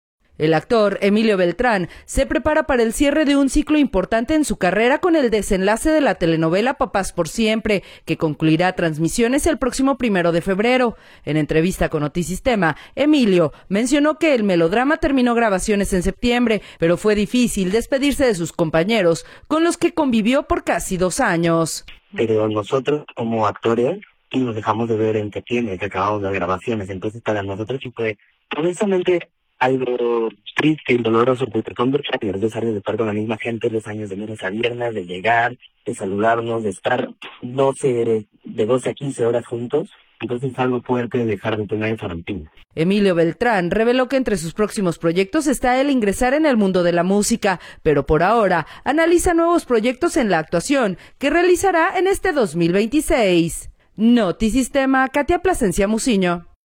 En entrevista con Notisistema